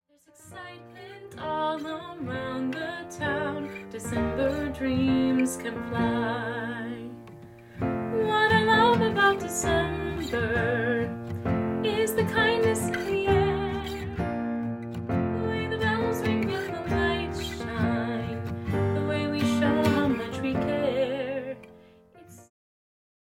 sweet, mid-tempo folk song
• Track with Guide Vocals